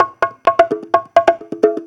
Conga Loop 128 BPM (7).wav